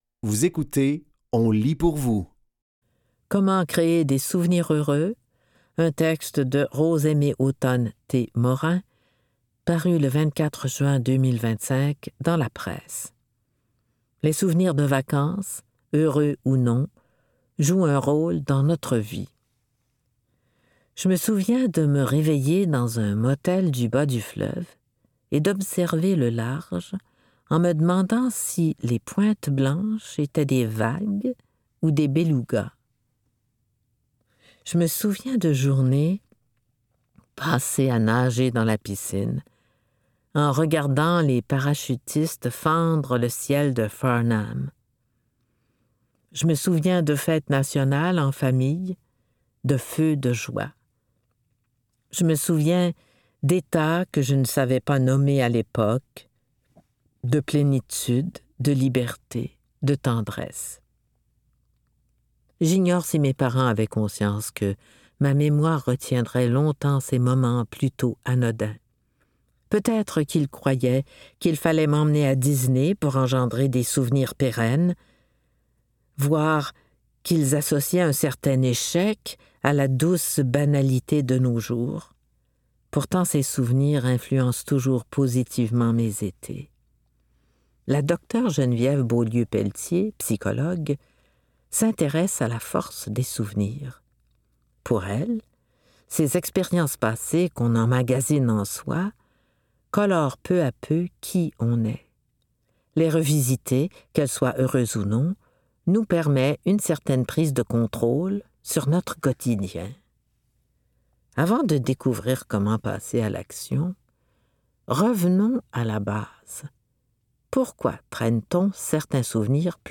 Dans cet épisode de On lit pour vous, nous vous offrons une sélection de textes tirés des médias suivants : La Revue du CREMIS, Le Devoir et Les Libraires.